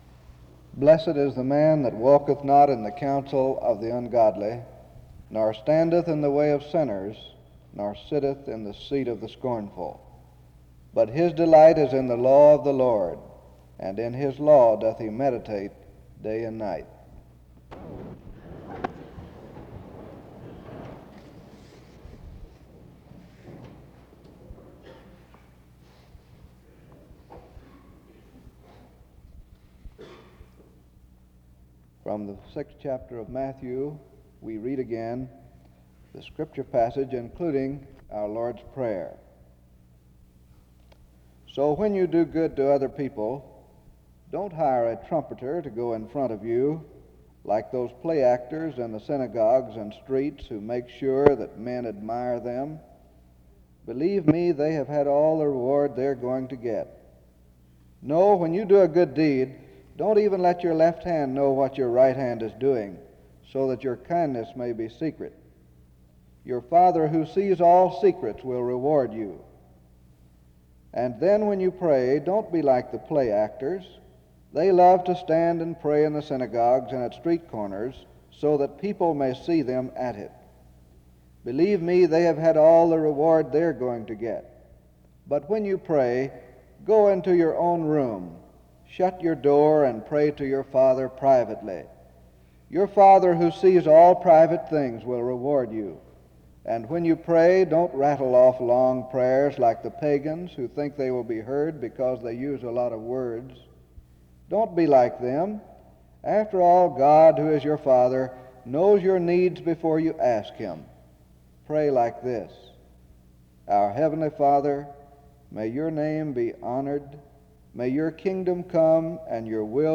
The service begins with the reading of Psalm 1:1-2 (00:00-00:30) and of Matthew 6:2-13 (00:31-02:18), along with prayer (02:19-03:17).
Singing follows these opening words (09:17-12:07). He begins by telling a checkers story to illustrate that sometimes we change the rules of the game in order that we will win or to suit ourselves (12:08-16:27). Most days, we statistically face two courses: that of courage and that of safety, the more advantageous of the two being the path of courage (16:28-34:48). He ends in prayer (34:49-38:02), and the service ends with the singing of the end of the Lord’s Prayer (38:03-38:47).